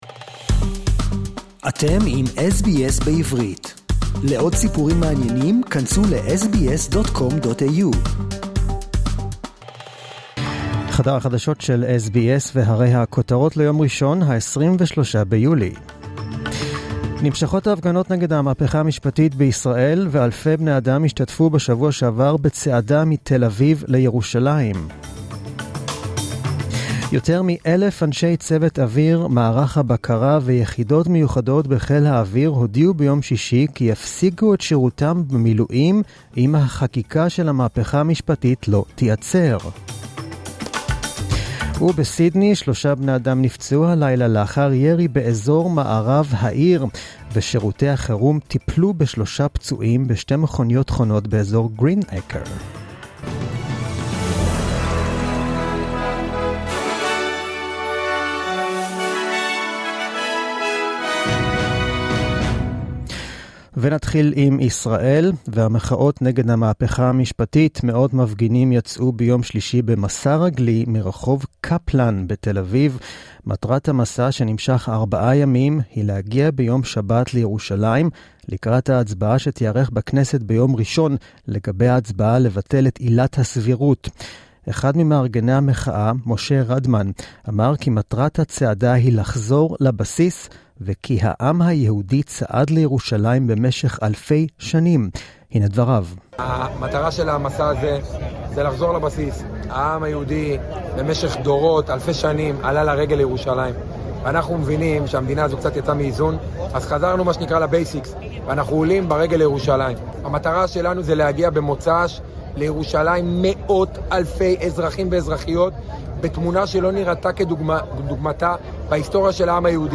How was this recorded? The latest news in Hebrew, as heard on the SBS Hebrew program